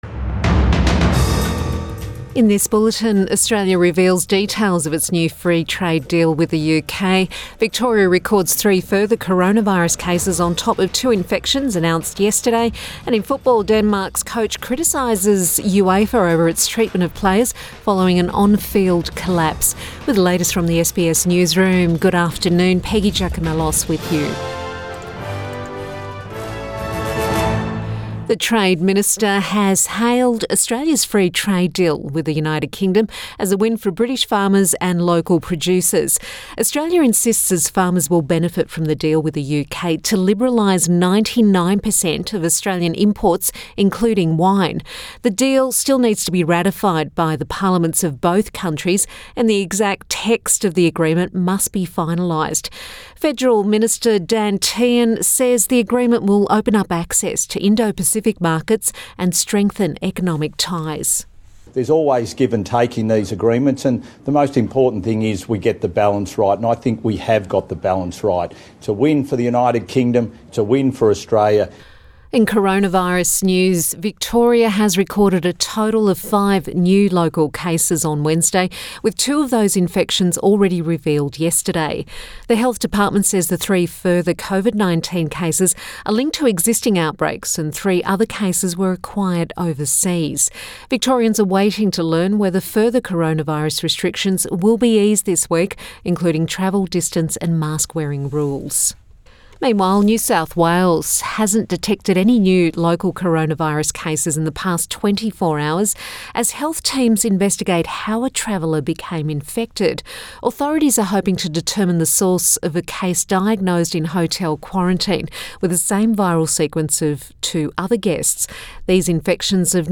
Midday bulletin 16 June 2021